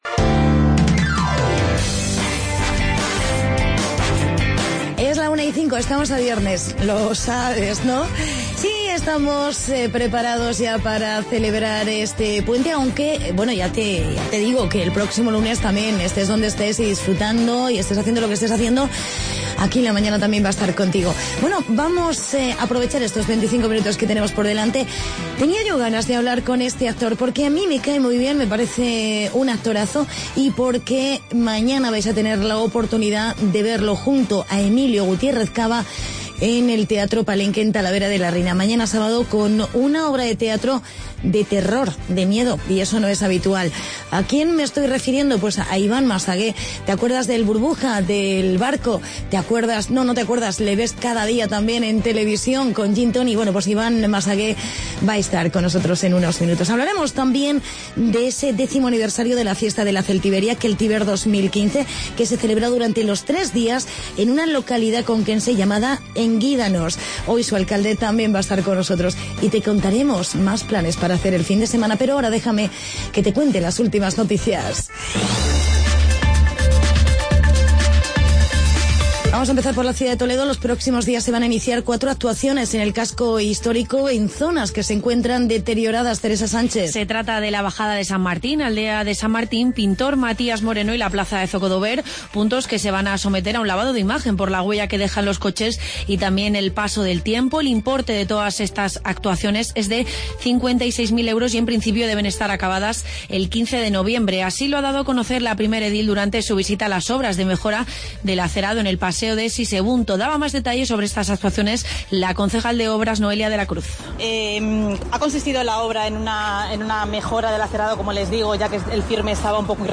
Entrevista con el actor Iván Massagué y con el alcalde de Engúidanos con "Keltiber 2015".